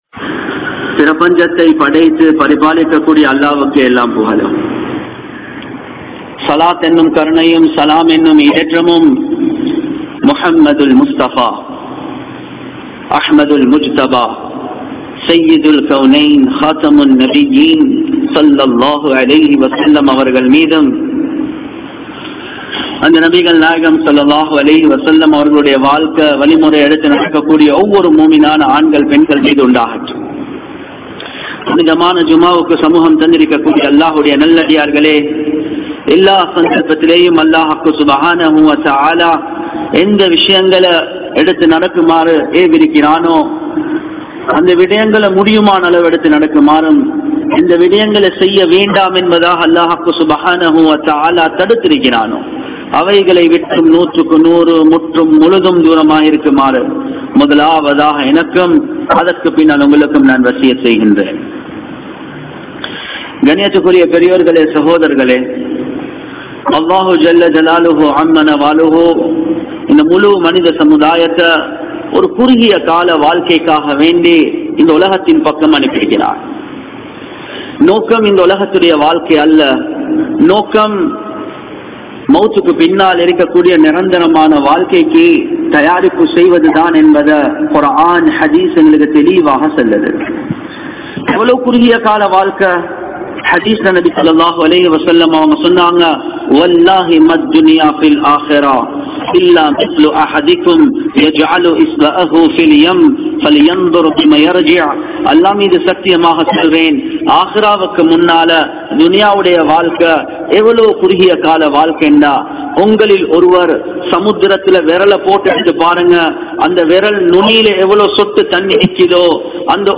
Neengal Allah`vin Neasana?? (நீங்கள் அல்லாஹ்வின் நேசனா??) | Audio Bayans | All Ceylon Muslim Youth Community | Addalaichenai
New Jumua Masjith